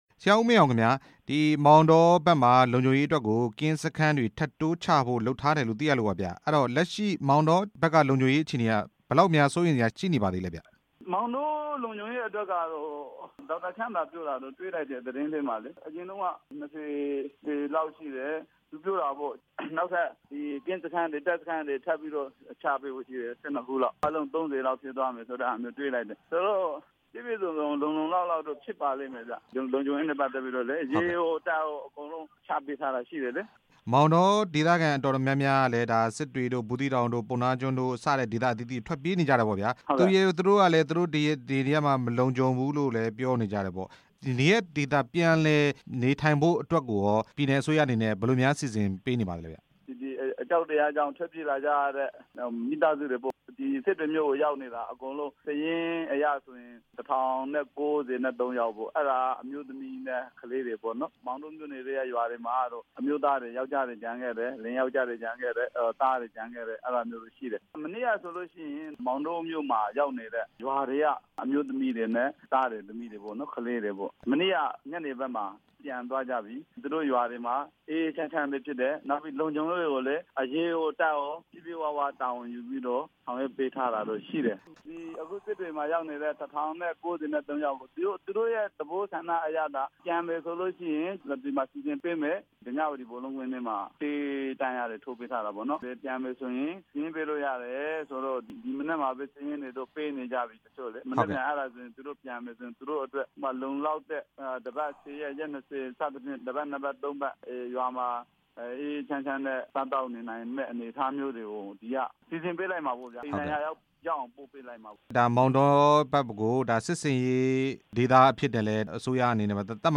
မောင်တောခရိုင်လုံခြုံရေး- ဆက်သွယ်မေးမြန်းချက်